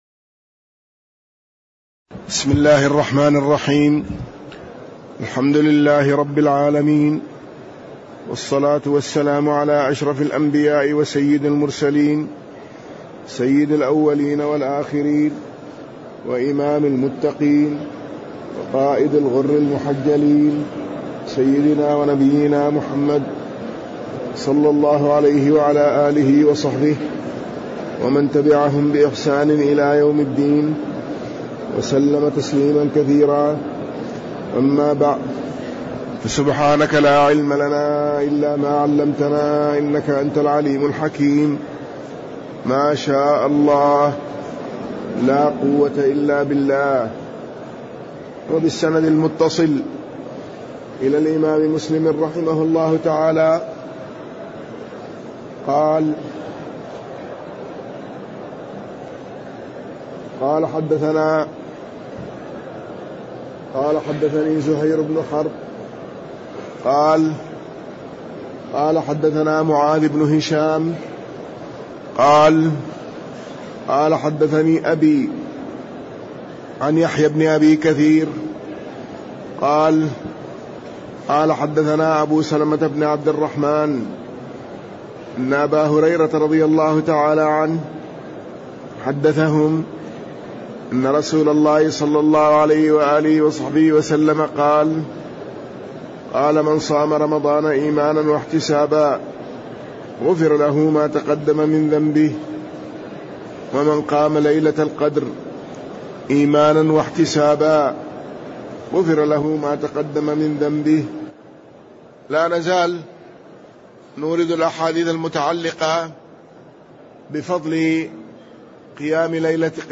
تاريخ النشر ٢٧ رمضان ١٤٣٠ هـ المكان: المسجد النبوي الشيخ